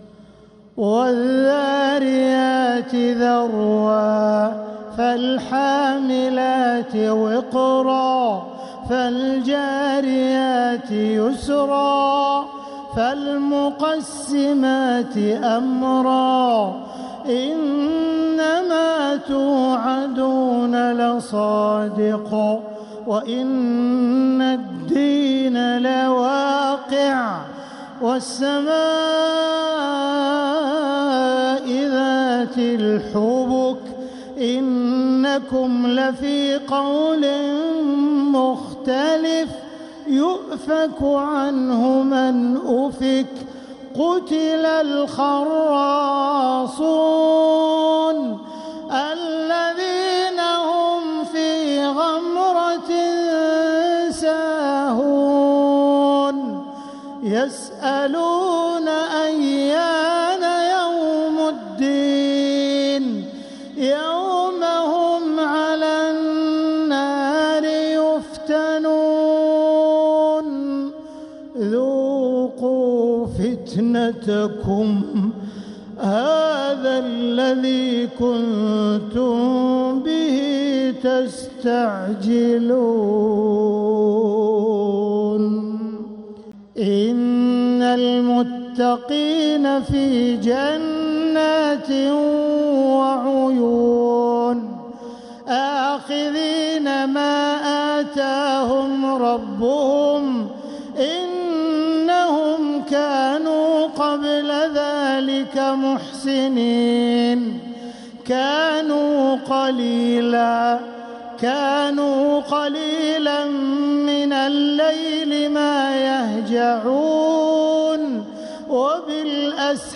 سورة الذاريات كاملة للشيخ عبدالرحمن السديس | فروض رجب - شعبان 1447هـ > السور المكتملة للشيخ عبدالرحمن السديس من الحرم المكي 🕋 > السور المكتملة 🕋 > المزيد - تلاوات الحرمين